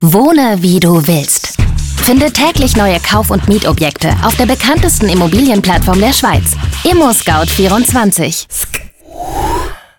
sehr variabel
Jung (18-30)
Schwäbisch
Commercial (Werbung)